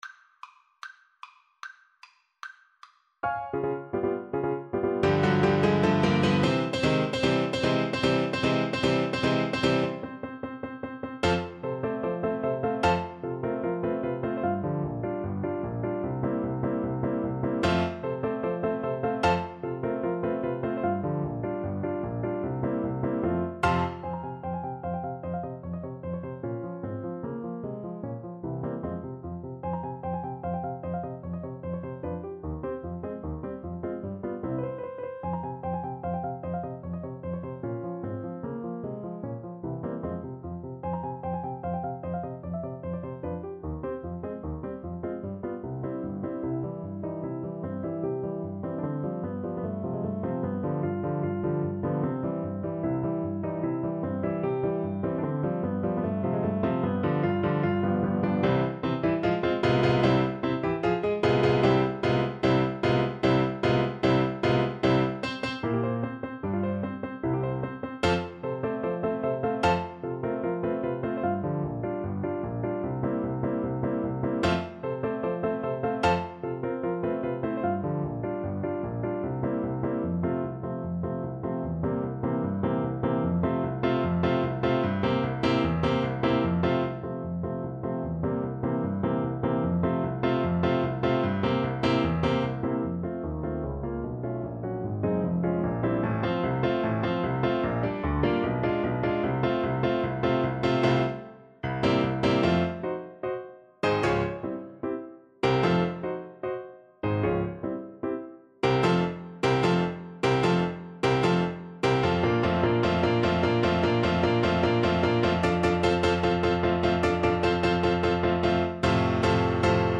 F major (Sounding Pitch) G major (Trumpet in Bb) (View more F major Music for Trumpet )
Allegro vivacissimo ~ = 150 (View more music marked Allegro)
2/4 (View more 2/4 Music)
Classical (View more Classical Trumpet Music)